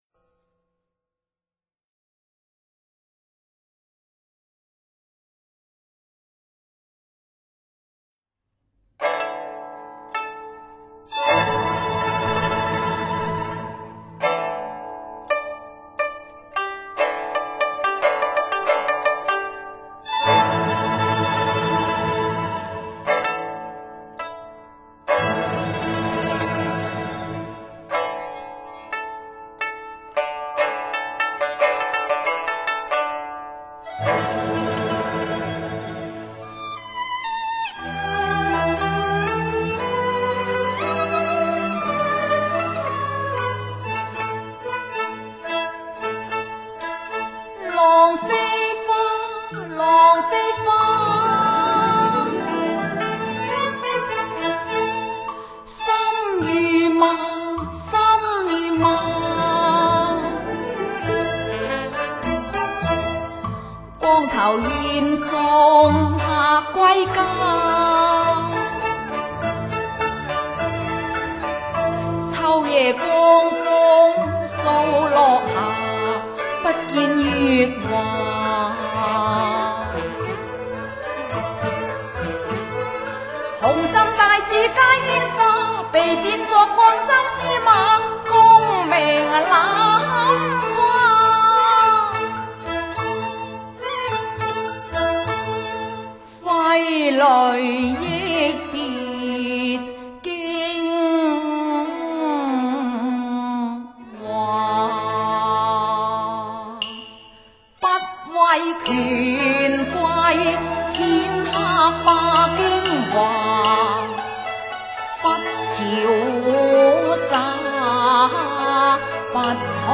经典粤曲琵琶弹唱